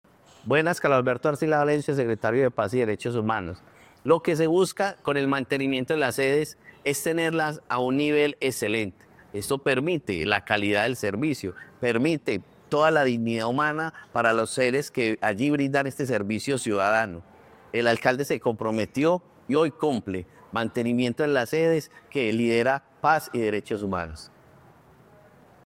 Palabras de Carlos Arcila, secretario de Paz y Derechos Humanos